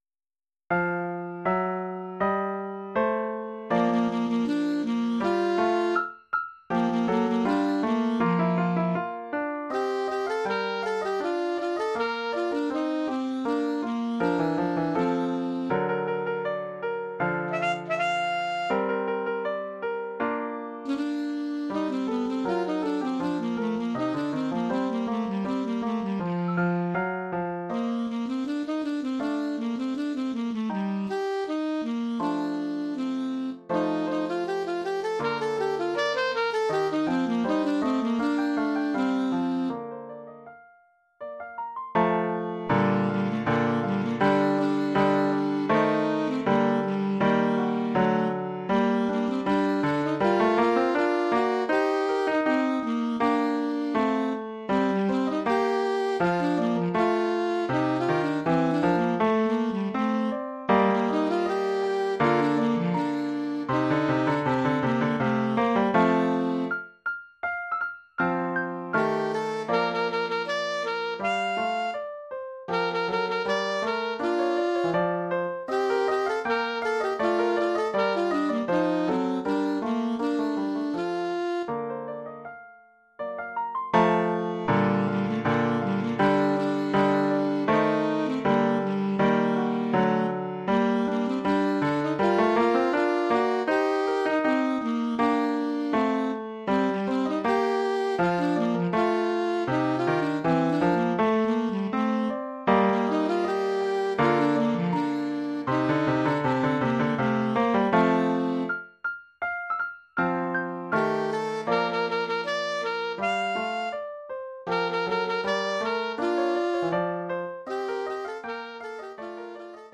Formule instrumentale : Saxophone alto et piano
Oeuvre pour saxophone alto et piano.
Niveau : 2e cycle.